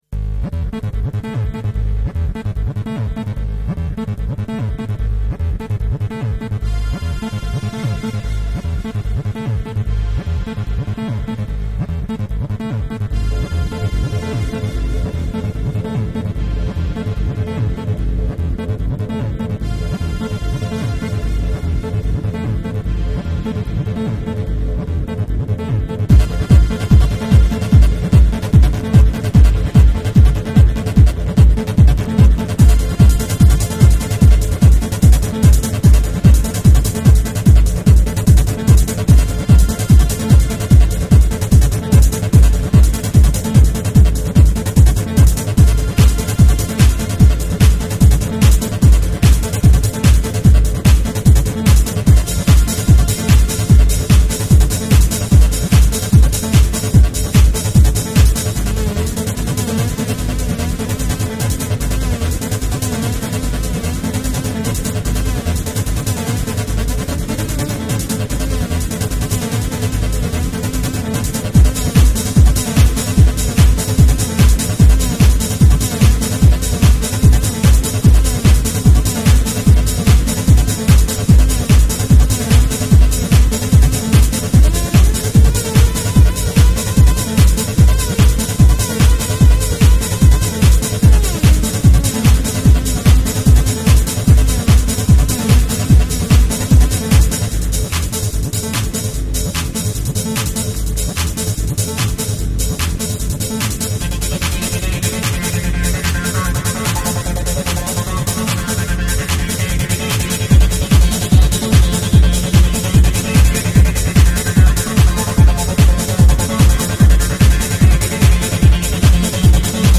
Файл в обменнике2 Myзыкa->Psy-trance, Full-on
Style: Psy-Trance, Goa